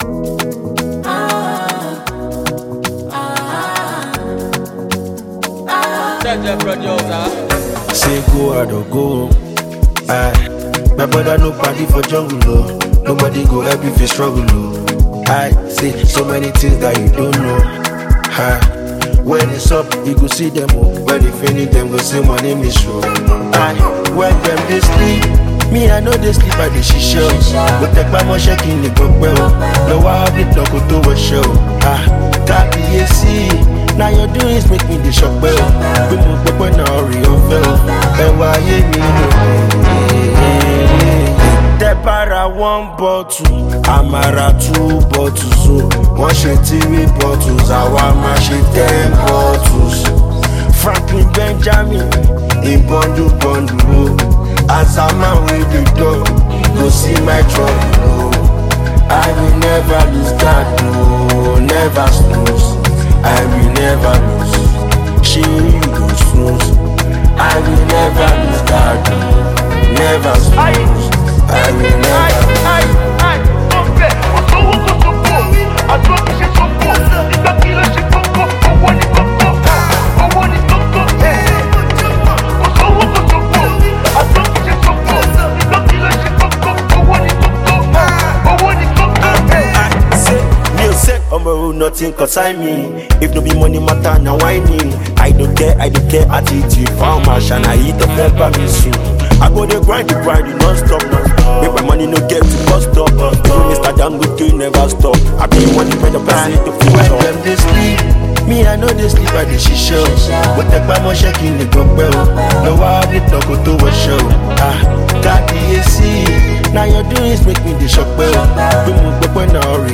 The catchy melody of this song will stay with you forever.